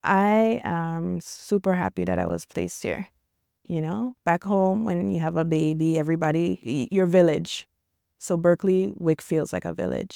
Testimonial 2 - WIC participant